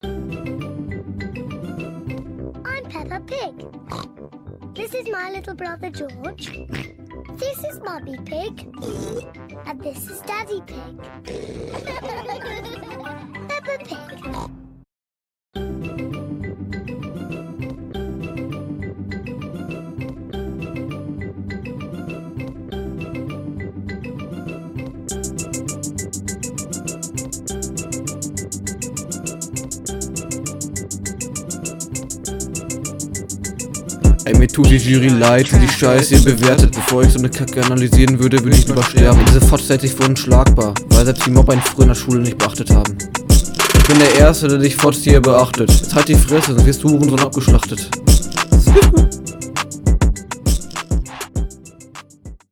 Hier sprichst du erneut statt zu rappen.
Hier bist du viel zu laut so wie deine Doppels sind auch viel zu laut …